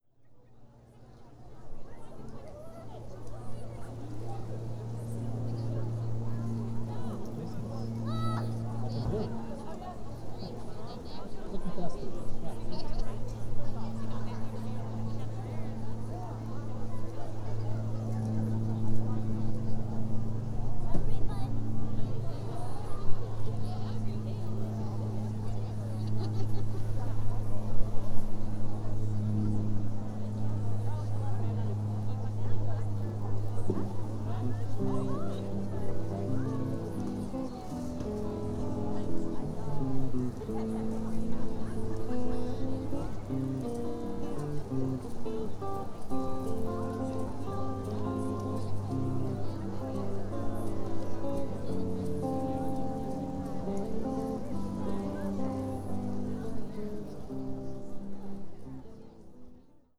The sounds of the winter cedar farmer’s market
The sounds of people mingling at the Winter Cedar Farmer’s Market accompanied by a plane overhead and a guitar solo from the duo playing music on stage.
After all the isolation due to COVID-19, being out with people and listening to the sounds of people chatting, dogs barking, and children laughing was very nice, in addition to the usual pleasure of local produce and artisanal wares.
Cedar-Farmers-Market.wav